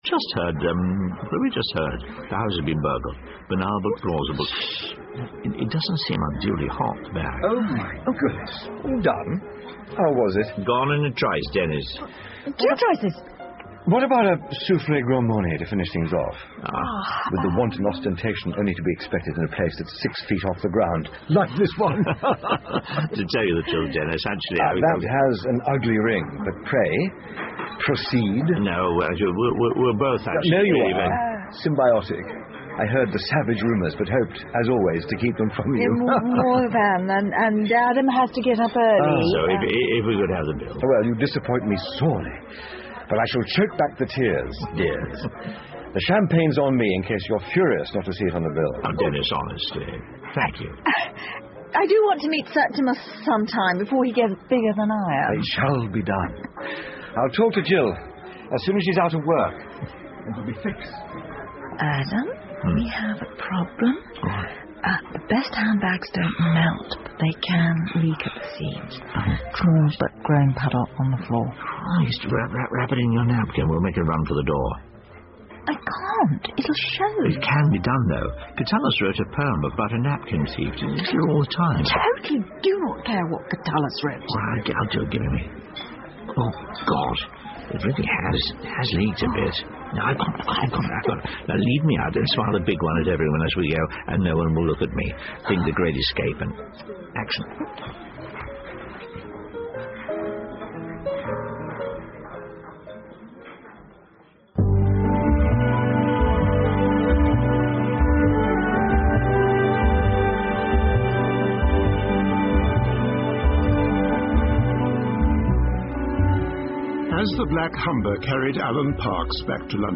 英文广播剧在线听 Fame and Fortune - 39 听力文件下载—在线英语听力室